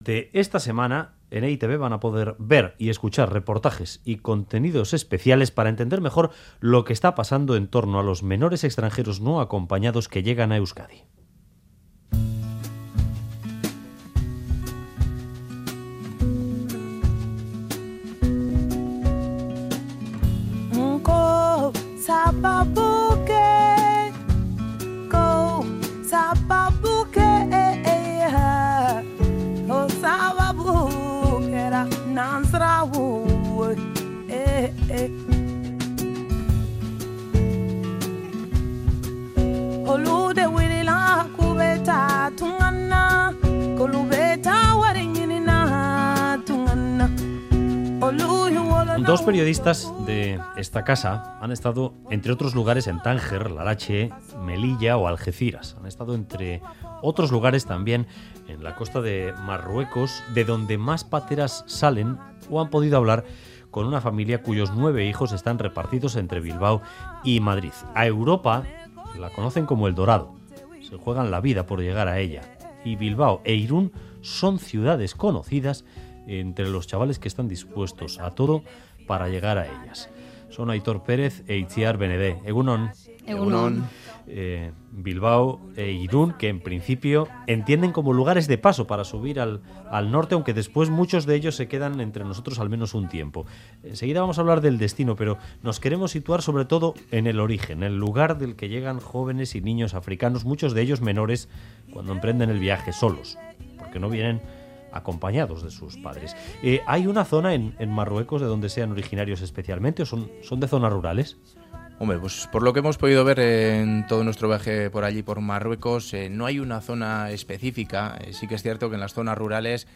Audio: Reportajes especiales sobre menores no acompañados para entender mejor lo que está pasando en torno a los menores extranjeros que llegan a Euskadi.